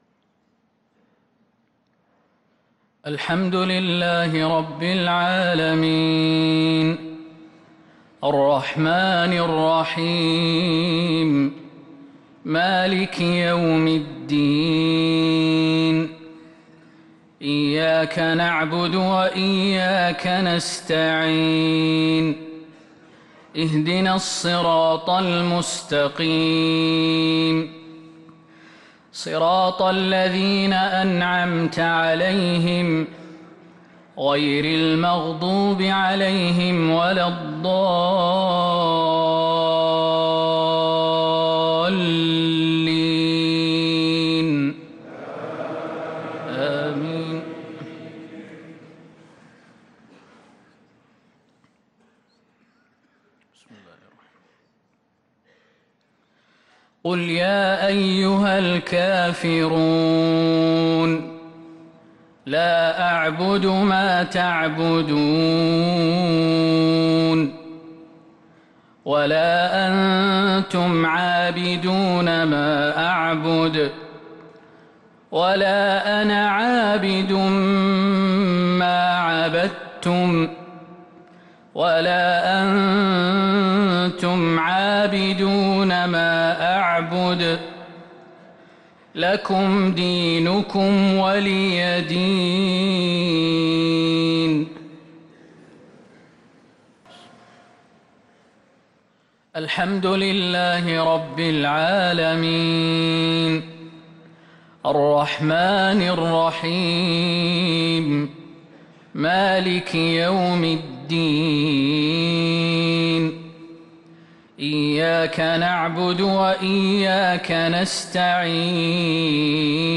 صلاة المغرب للقارئ خالد المهنا 25 جمادي الأول 1444 هـ
تِلَاوَات الْحَرَمَيْن .